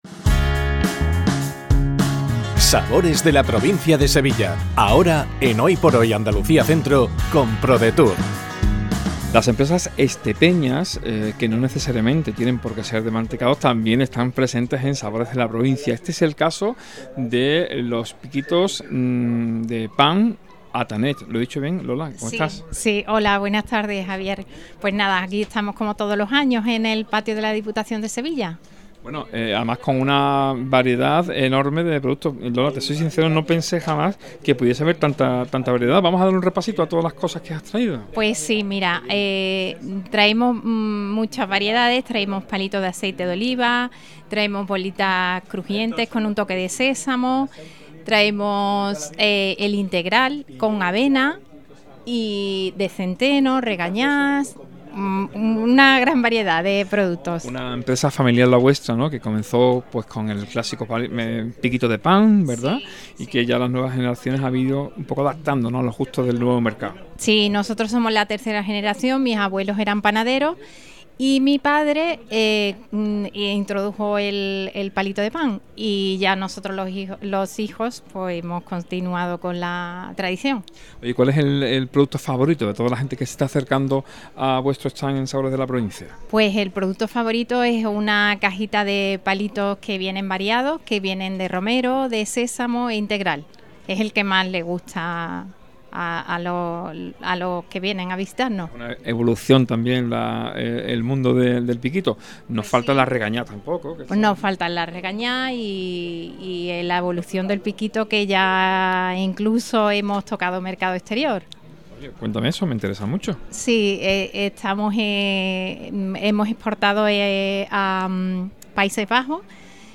ENTREVISTA PICOS ATANET